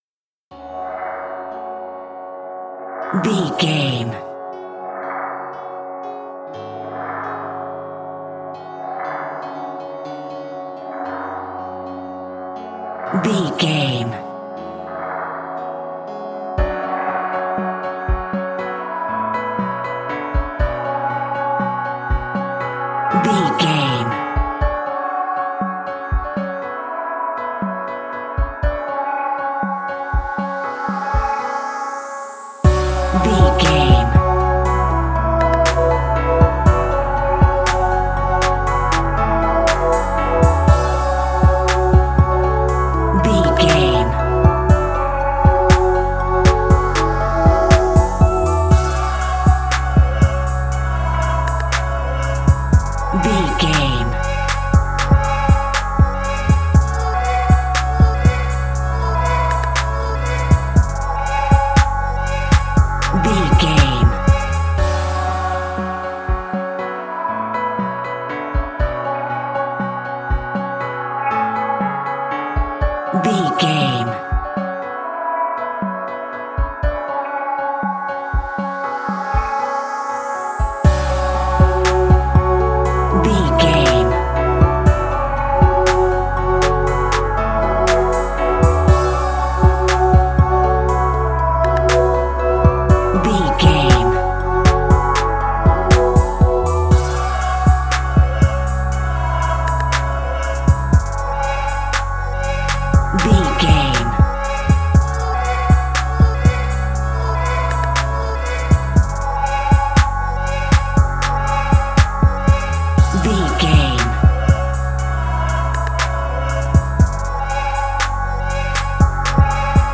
Aeolian/Minor
F#
instrumentals
chilled
laid back
groove
hip hop drums
hip hop synths
piano
hip hop pads